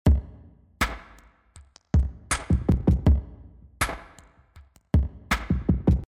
Ich fange die Peaks mit dem Vintage Limiter aus iZotope Ozone 7 ab.